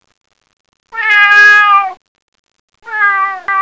Cat
CAT.wav